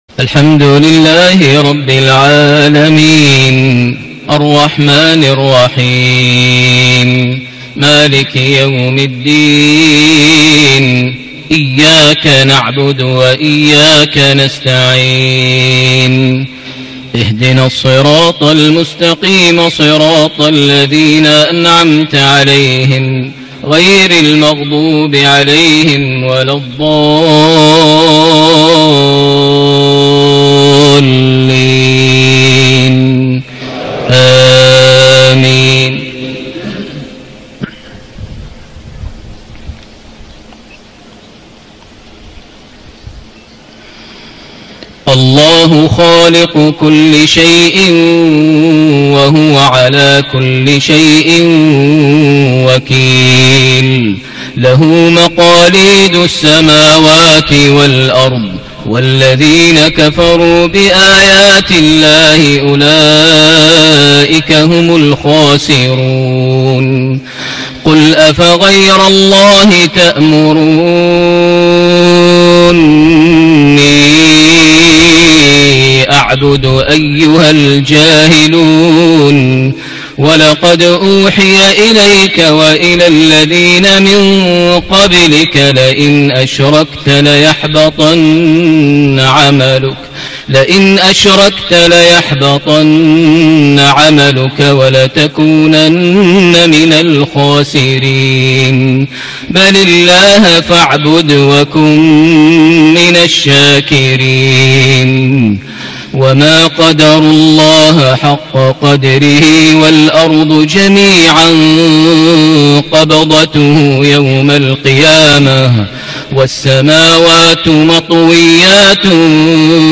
صلاة العشاء ٢ رجب ١٤٣٨هـ خواتيم سورة الزمر > 1438 هـ > الفروض - تلاوات ماهر المعيقلي